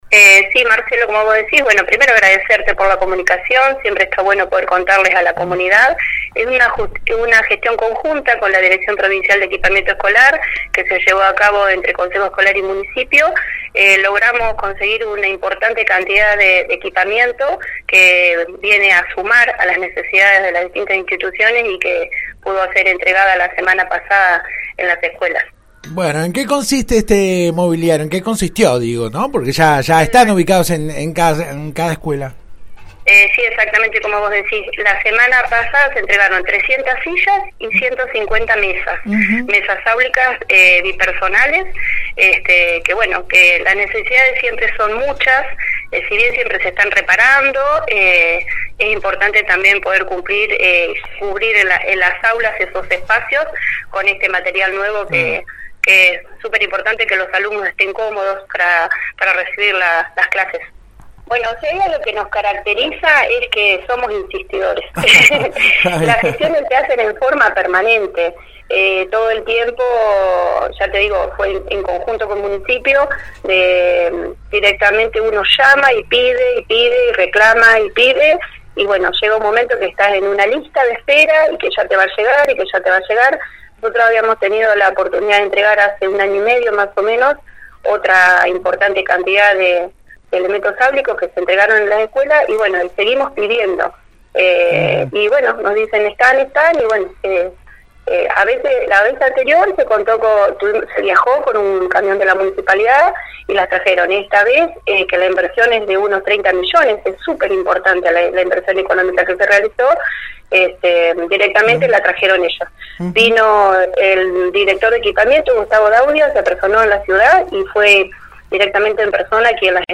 (Foto archivo) Luego de la entrega de mobiliario a diversas escuelas florenses la 91.5 habló con la actual presidenta del Consejo Escolar quien brindó detalles de la gestión realizada ante la Dirección Provincial de Equipamiento Escolar.